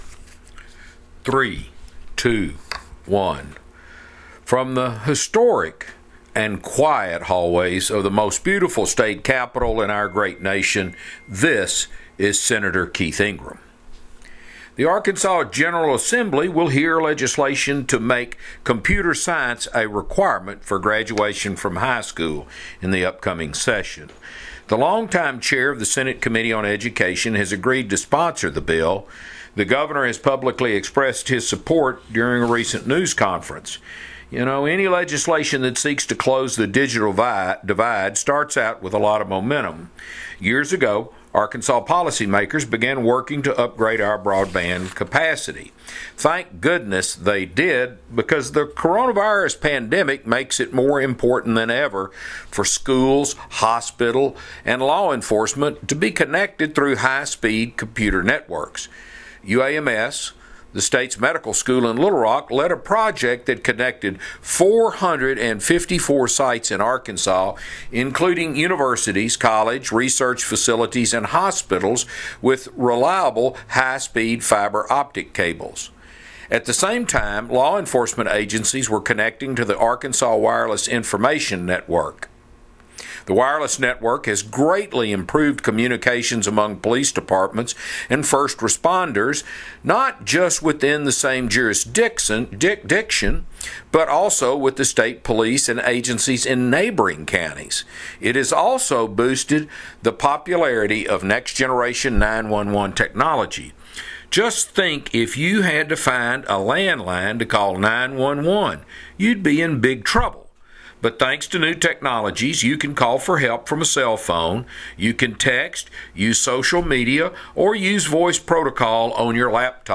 Weekly Address – December 4, 2020 | 2020-12-04T16:21:33.701Z | Sen.